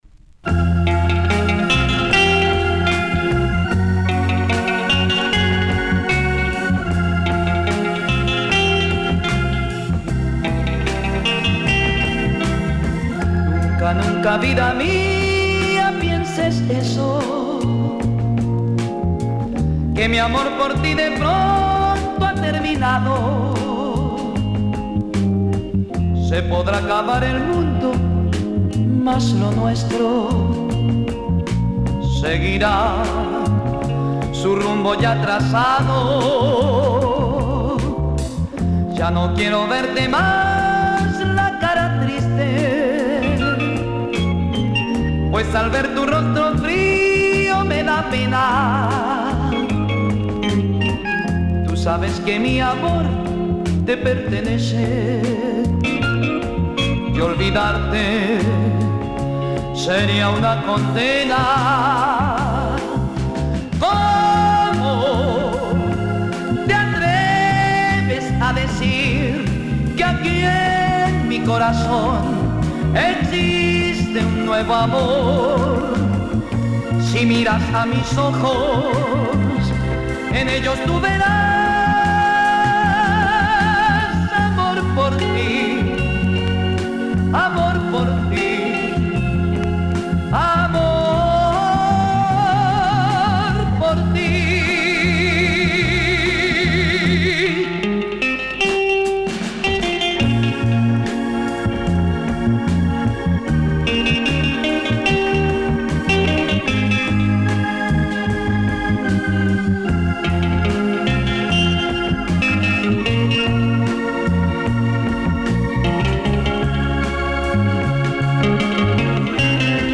Published February 21, 2010 Boleros y Baladas Comments
I threw some great break-beat sounding boleros